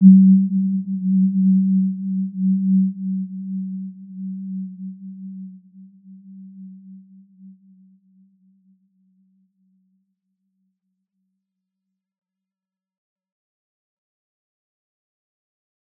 Warm-Bounce-G3-p.wav